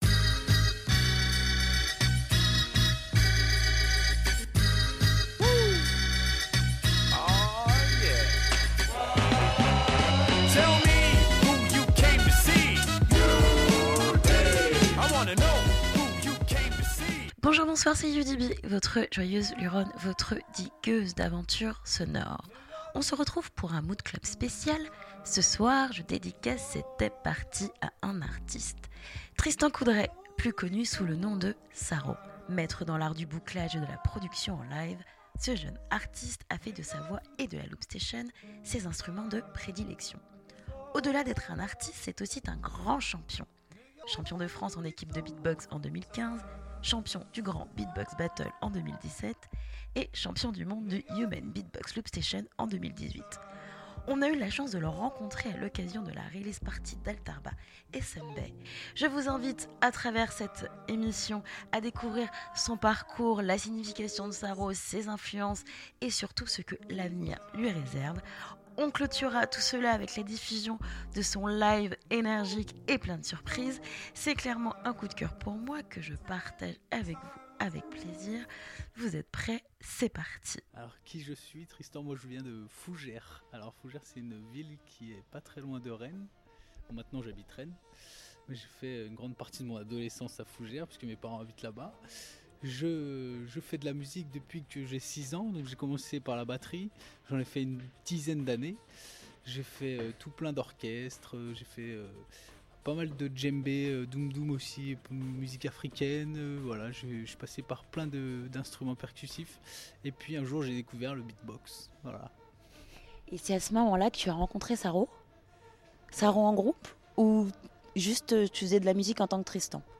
Ce soir c'est "MoodTapes" Party .